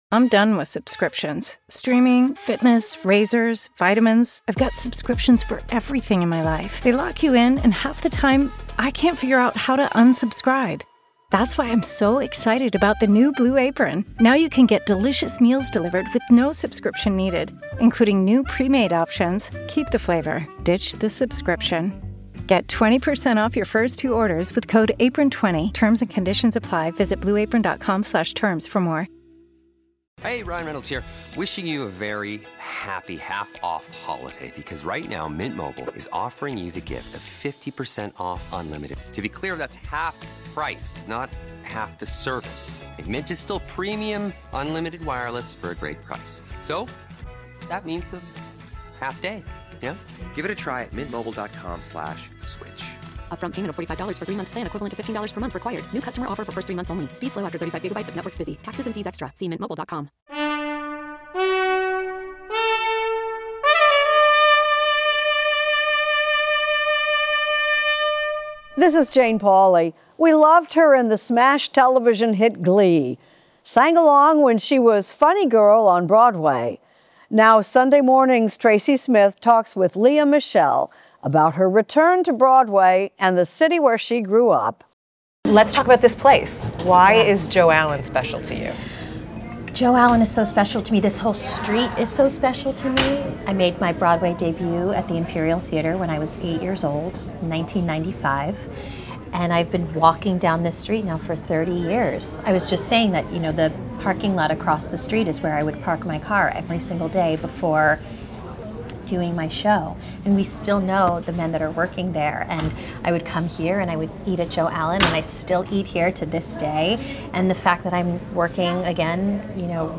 Extended Interview: Lea Michele